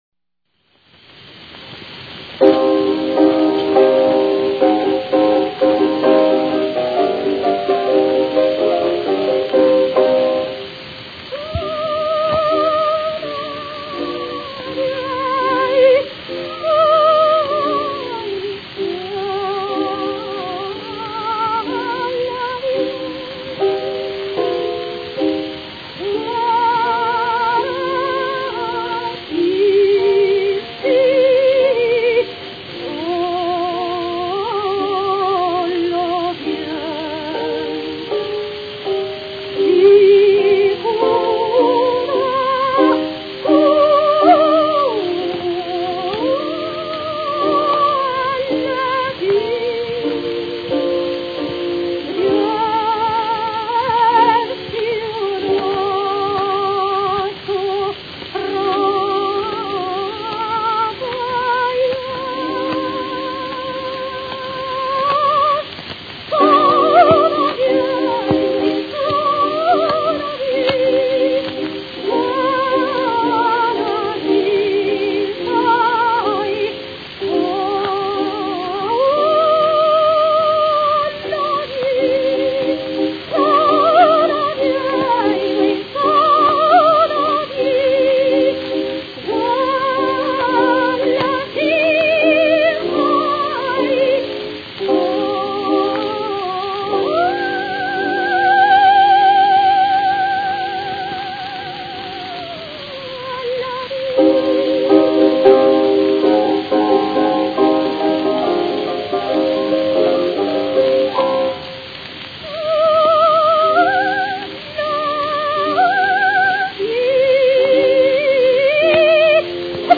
Olimpia Boronat is a lyric coloratura soprano of delicacy, sweetness and charme.
Her tendency to vary nuances by lenghty morendos may frequently disturb the musical line. In full voice the tone is driven against the hart palate in the upper register and the highest notes are frequently not properly pitched. It is artificial singing, and when listening to this soprano I imagine her standing in one of those Russian “salons” singing Alabiev’s The Nightingale followed by Zardo’s Desiderio.
Solovei - The Nightingale (transposed down a full tone to C minor / Alabiev /  G & T 1904)